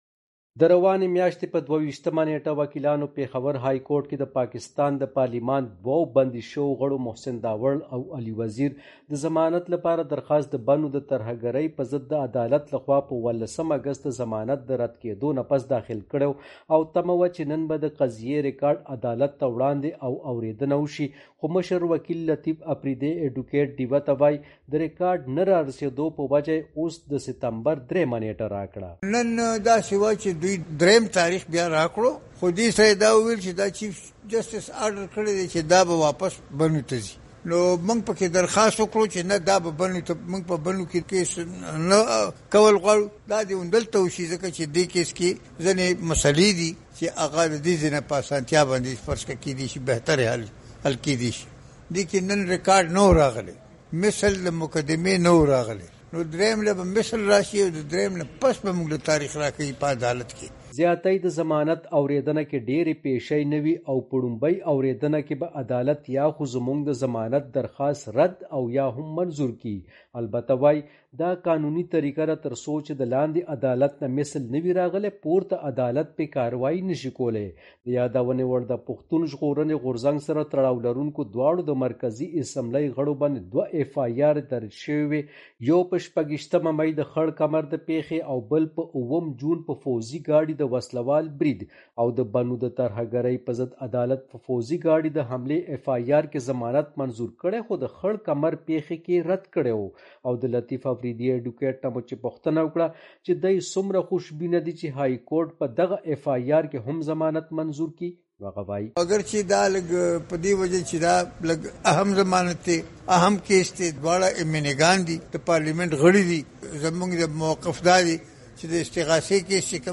په رپورټ کې اوریدی شئ: